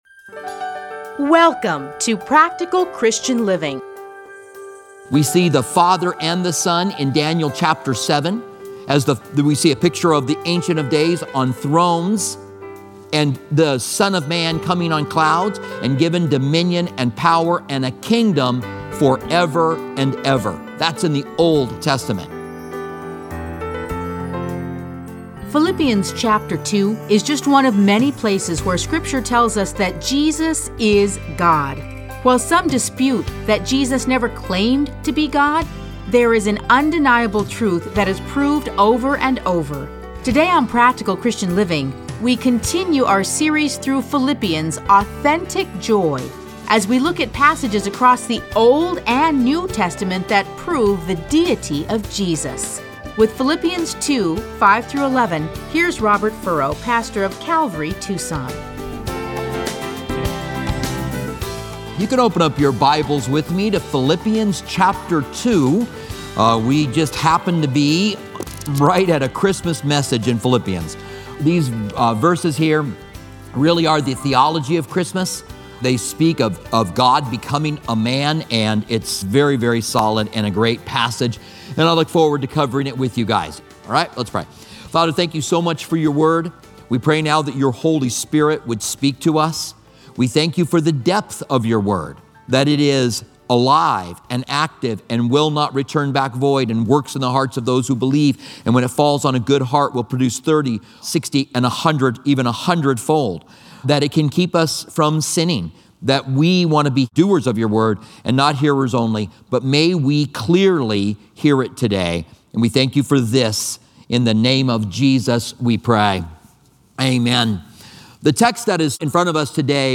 Listen to a teaching from A Study in Philippians 2:5-11.